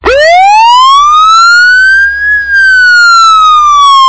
SFX消防车行驶中喇叭音效下载